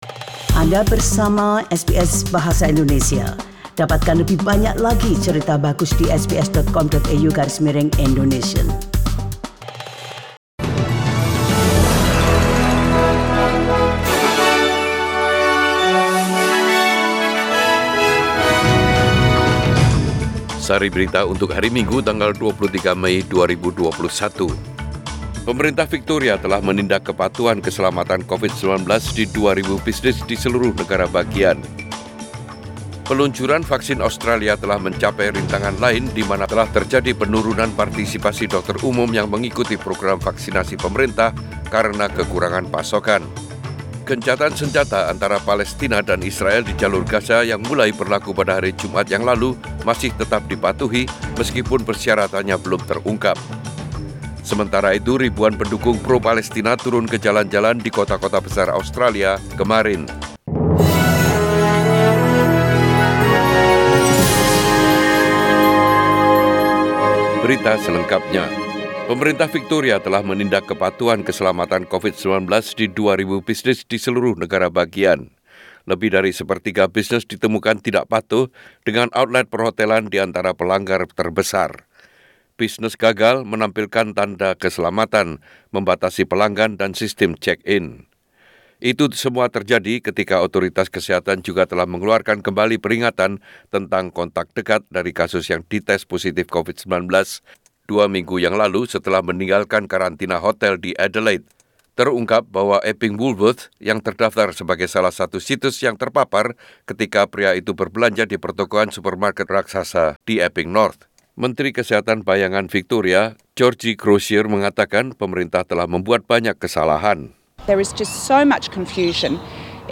SBS Radio News in Bahasa Indonesia - 23 May 2021